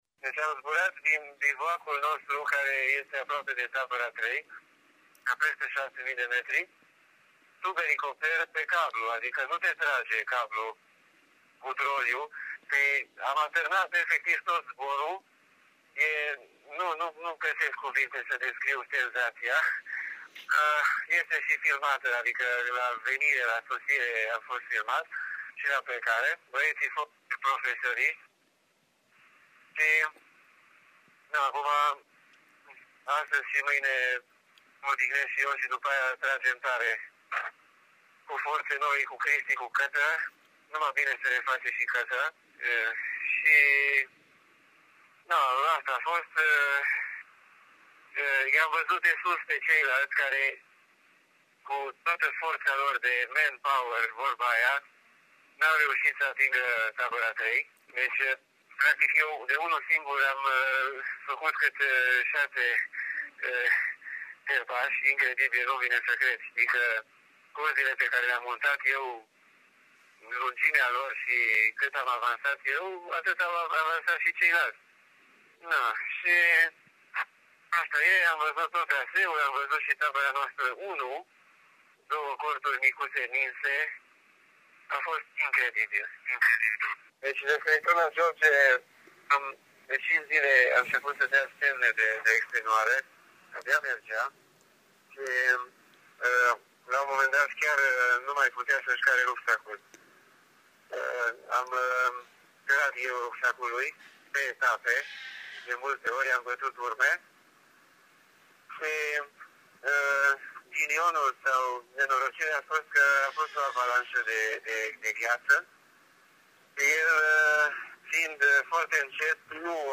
A beszélgetés műholdas telefonon keresztül rögzítették, és megtalálható a hegymászó honlapján is.